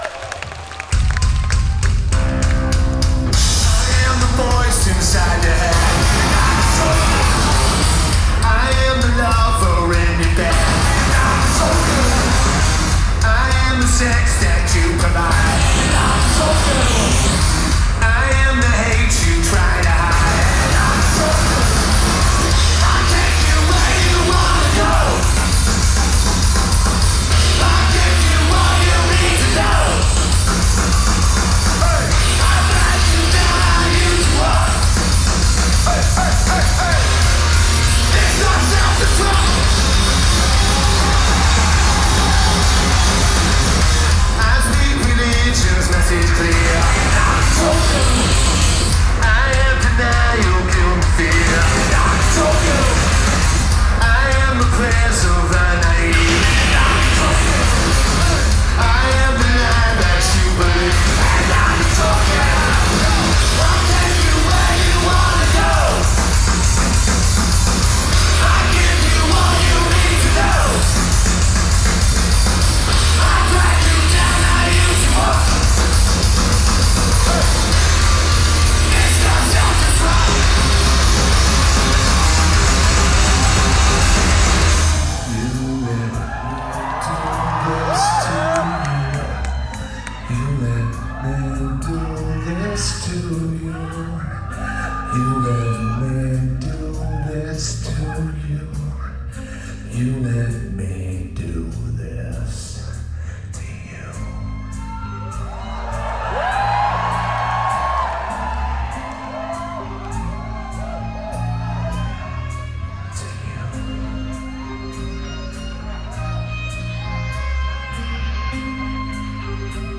Studio Coast